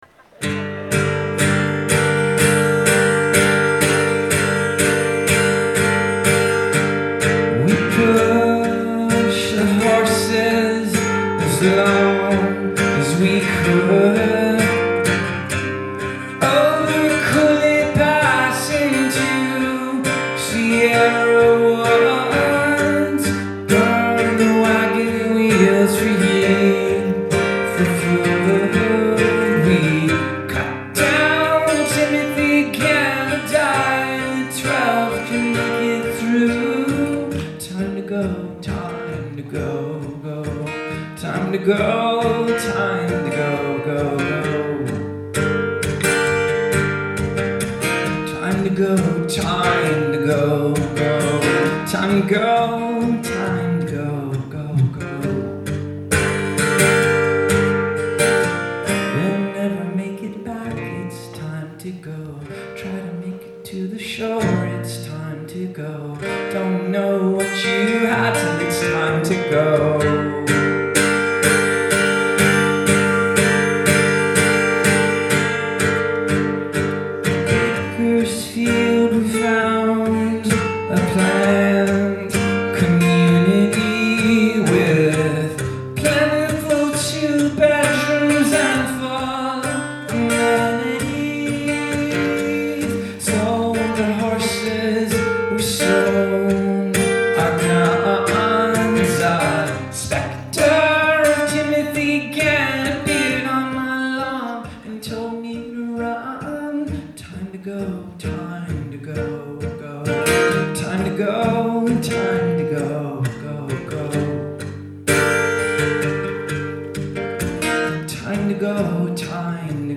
Live at the Somerville Theatre